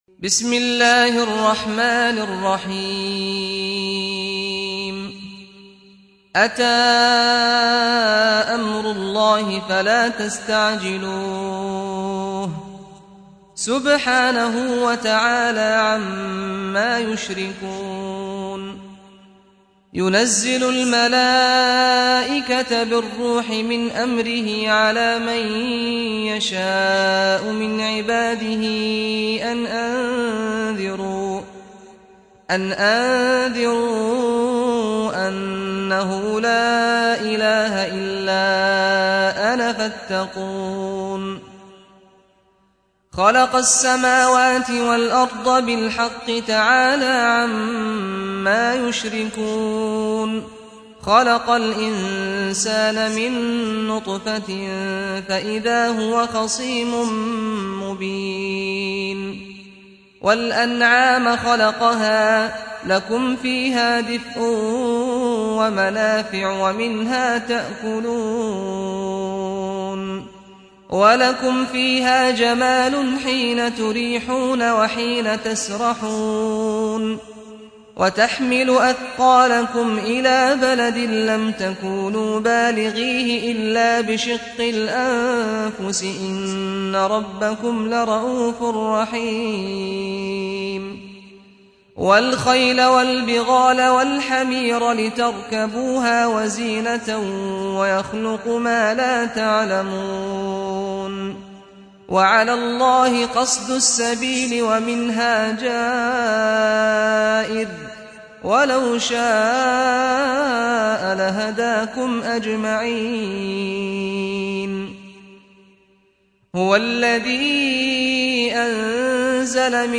سورة النحل | القارئ سعد الغامدي